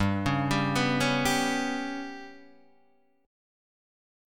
GMb5 chord {3 4 5 4 2 3} chord